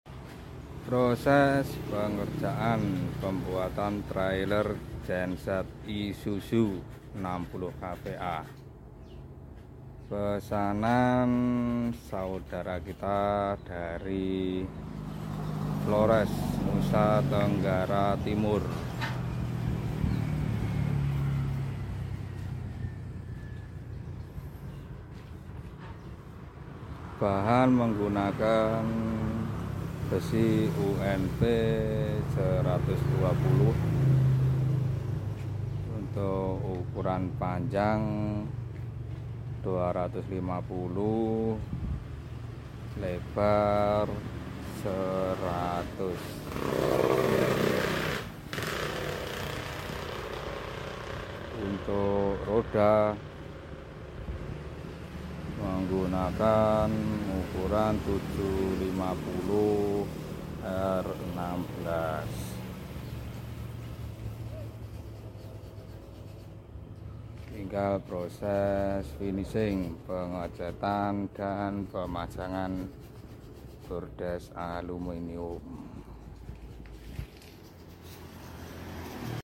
proses trailer genset isuzu 60kva